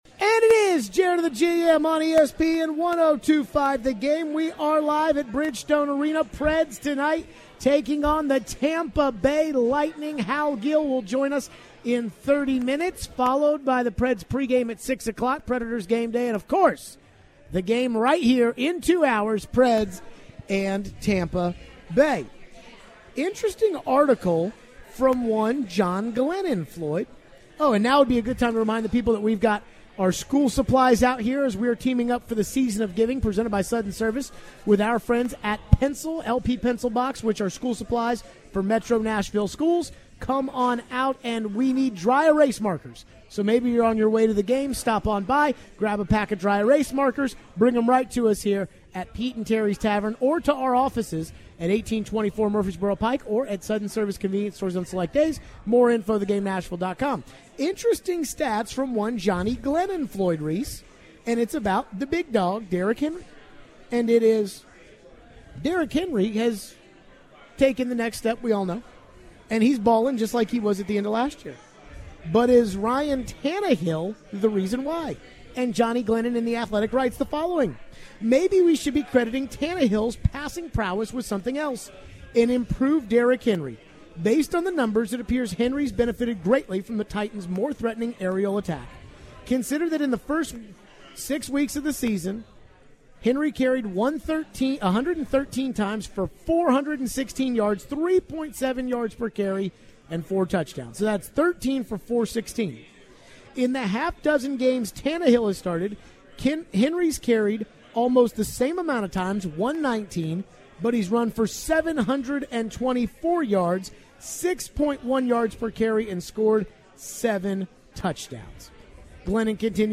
Plus, they take your phone calls on the Titans.